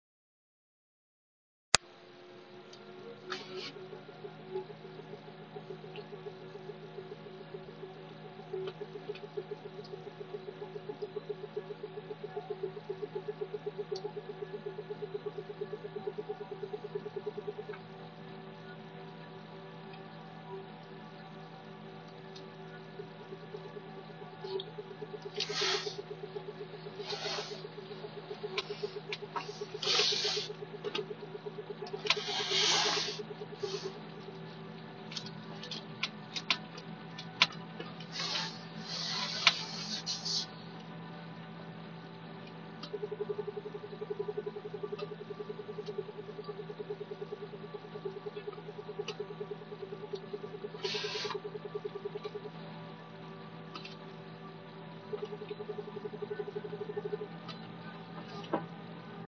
FYI - I recorded the clicking sound I'm hearing using my IPhone's Voice Memo app. In this recording, I'm in an empty parking lot accelerating very slowly. The car never exceeds 10 MPH. Attachments 2019 Kona EV Clicking Noise.mp3 2019 Kona EV Clicking Noise.mp3 928.3 KB · Views: 202